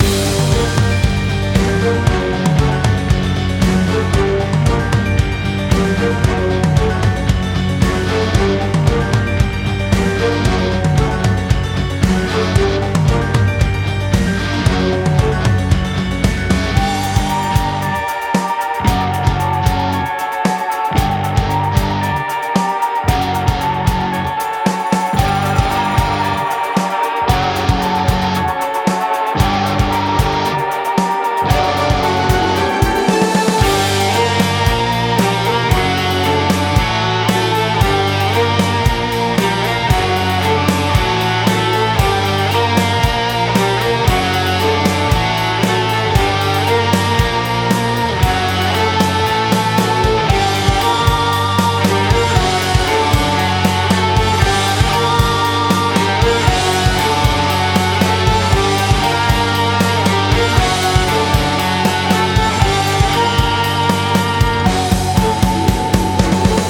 Genre: classical, rock.